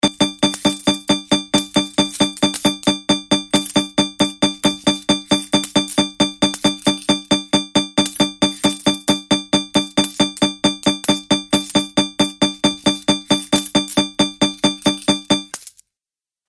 دانلود صدای هشدار 26 از ساعد نیوز با لینک مستقیم و کیفیت بالا
جلوه های صوتی